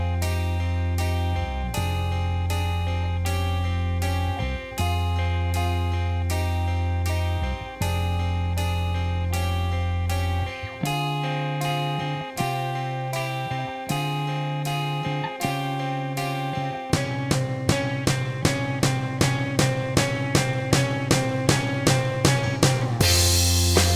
no Backing Vocals Rock 5:24 Buy £1.50